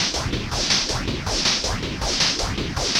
RI_RhythNoise_80-02.wav